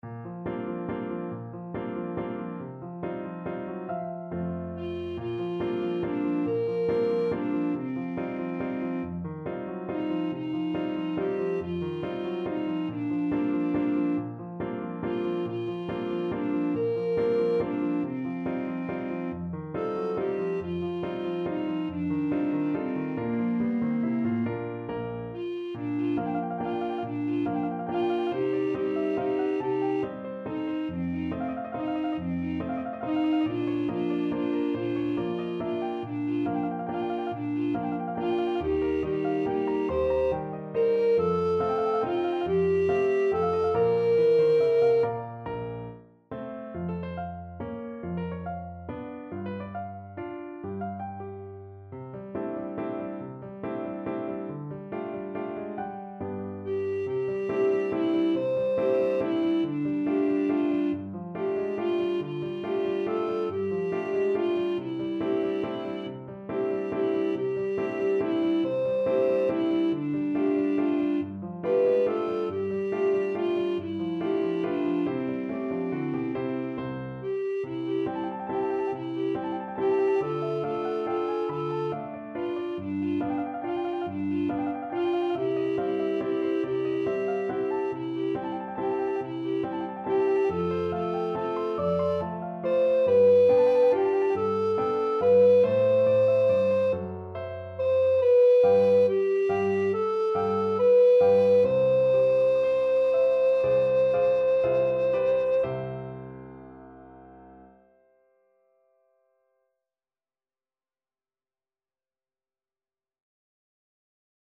印尼民謠
2-part treble choir and piano
世俗音樂